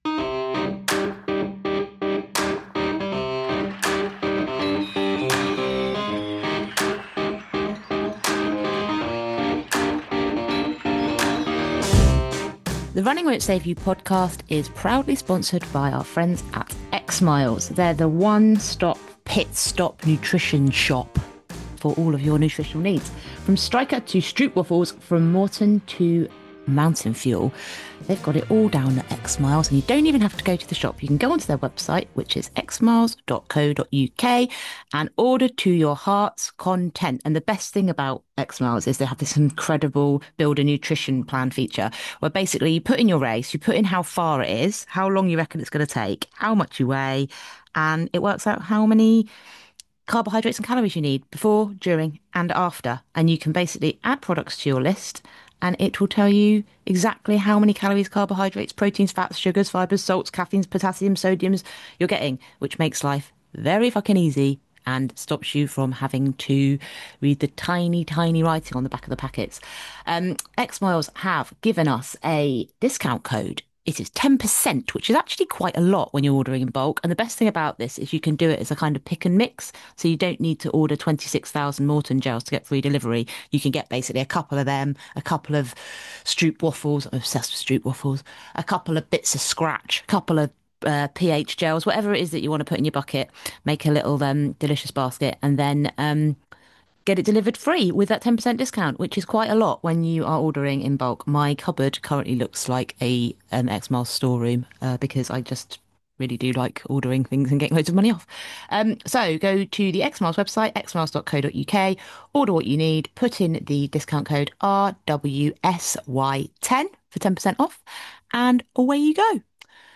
It's the morning after the two days before and we have an exclusive interview